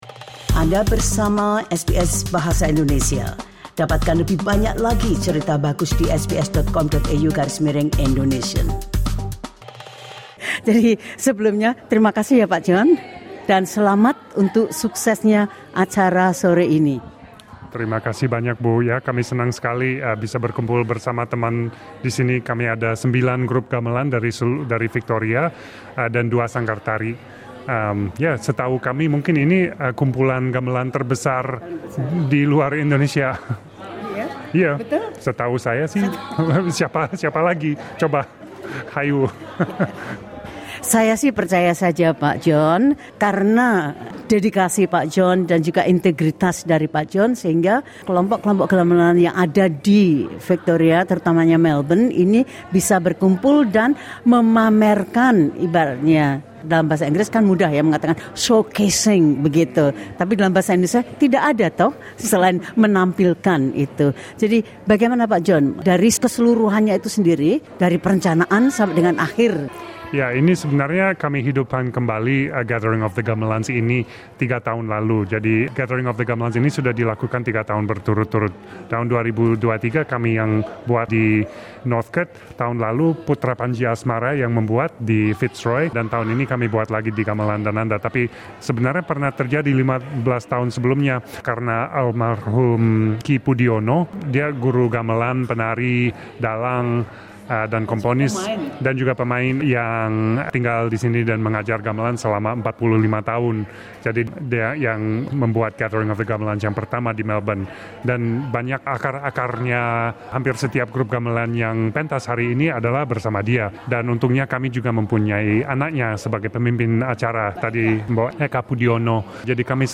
She told SBS Indonesian about her love and joy of playing gamelan.